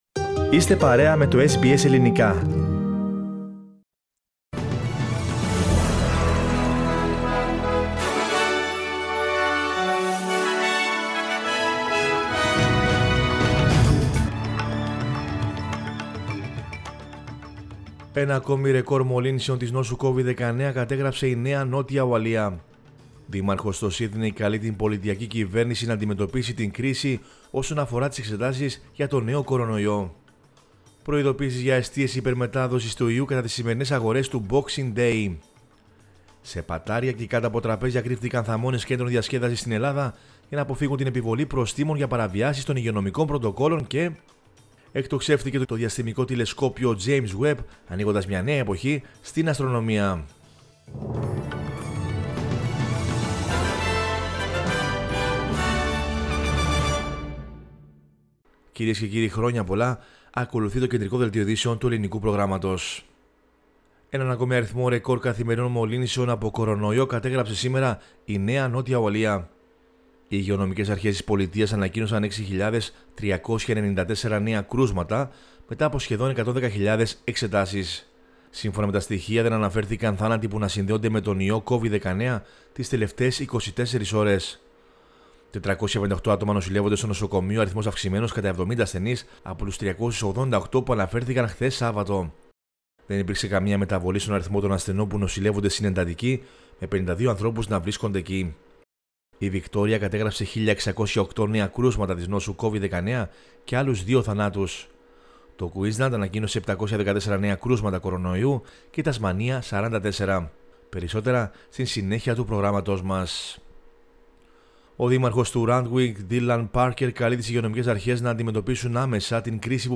News in Greek from Australia, Greece, Cyprus and the world is the news bulletin of Sunday 26 December 2021.